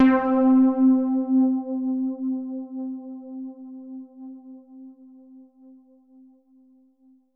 SOUND  C3.wav